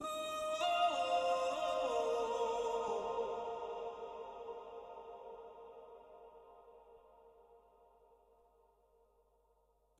FX 1.wav